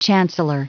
Prononciation du mot chancellor en anglais (fichier audio)
Prononciation du mot : chancellor